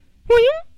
卡通投弹口哨
描述：Freesound 171760的一个拉伸位，投弹口哨大约在一半的位置。
Tag: 下降 上升 警报器 苛刻 向下 向上 171760 卡通 秋天 哨子 炸弹 噪音